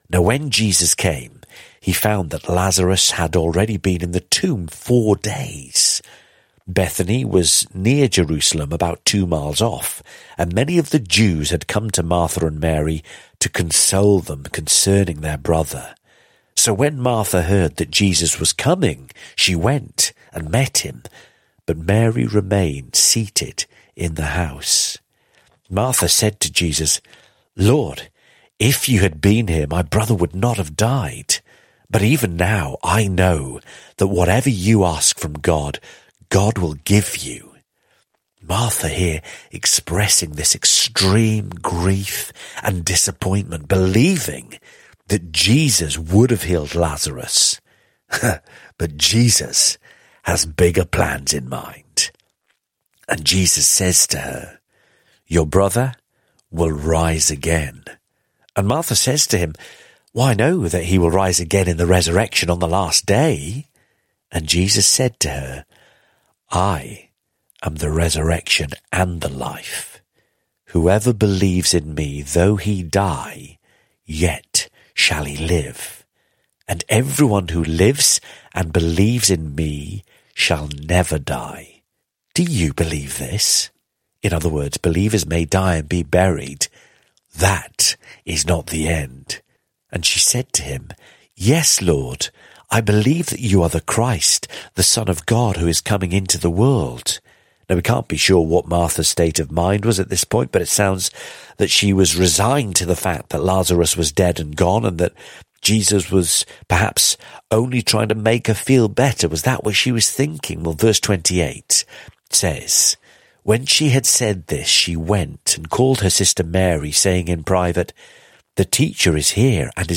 teachers on the daily Bible audio commentary